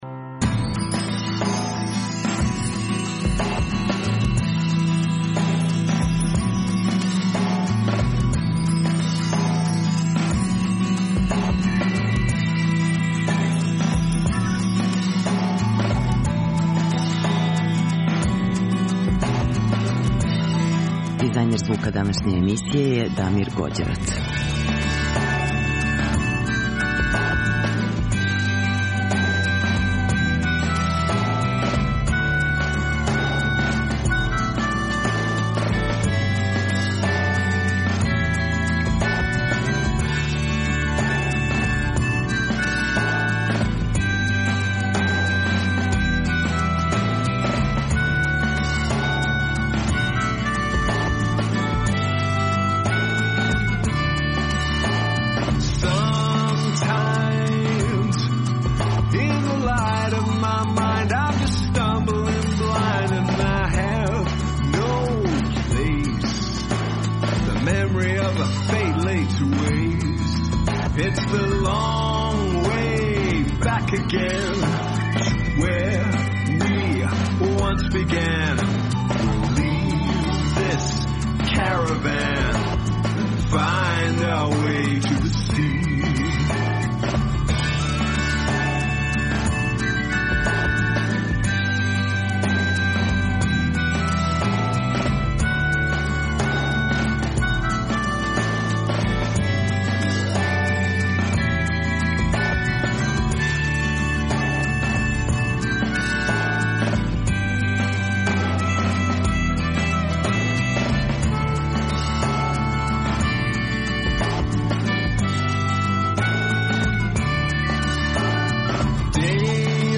Емитујемо интервју са Мајклом Ђиром, оснивачем легендарне америчке групе Swans, поводом концерта који ће се одржати у Дому омладине.